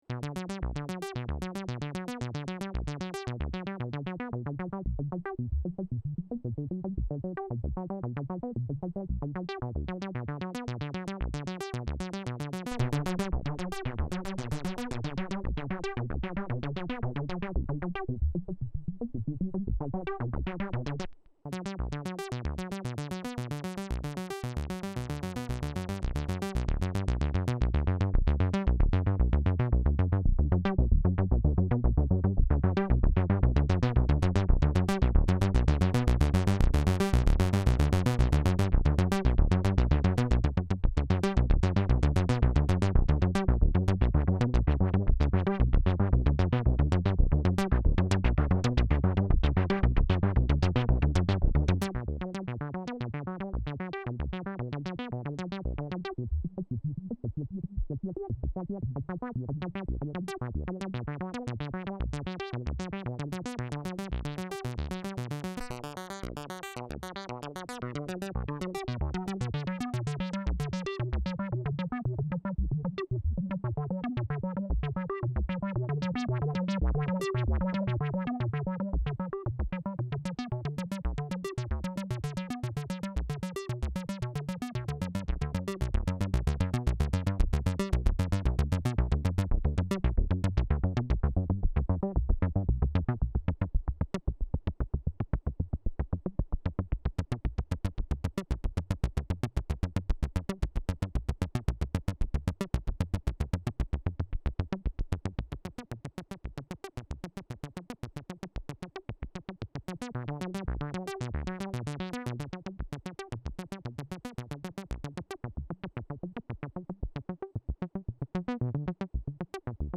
Roland Boutique , JU06 synthesizer
Wave Shapes pulse square saw
DETAILS nothing special. it has a chrous. well the secret is: the juno sounds good. no more or less. the juno 106 can be controlled by Midi SysEx Messages in realtime.
SOUND 80ies pads and sounds